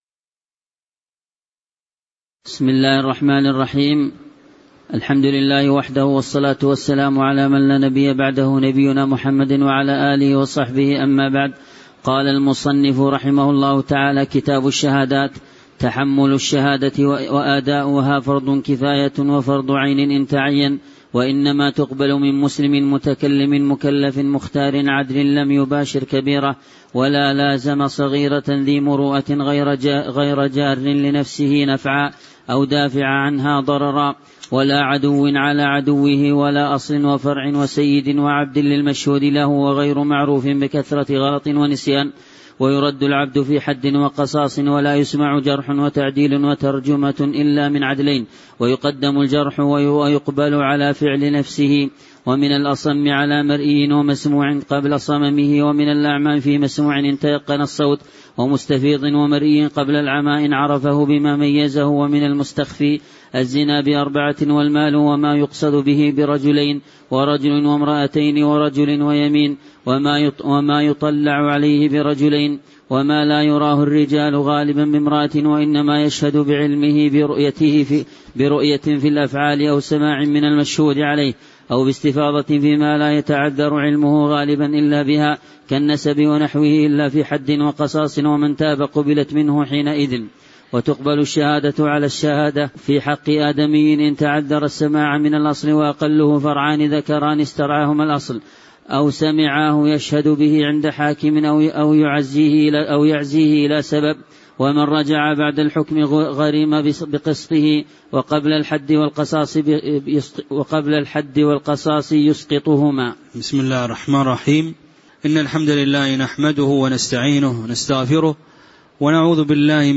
تاريخ النشر ٢٣ شوال ١٤٣٩ هـ المكان: المسجد النبوي الشيخ